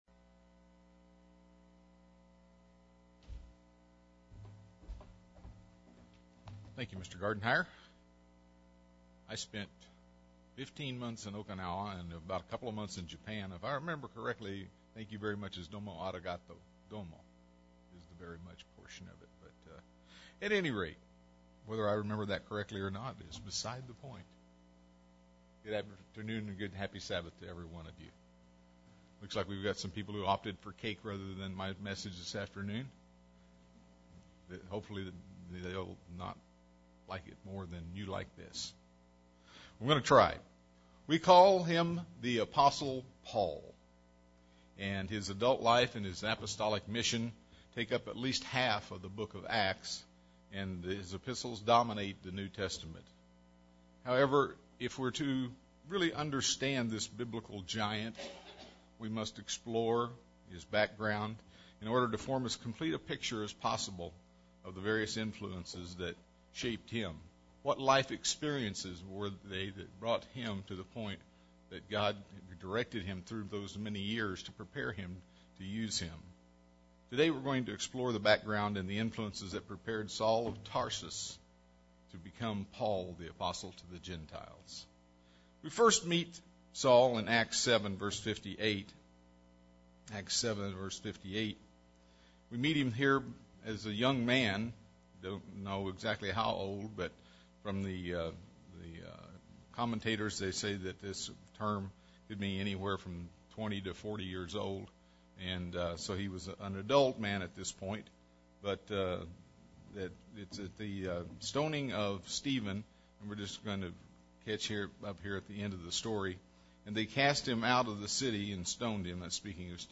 UCG Sermon Studying the bible?
Given in San Diego, CA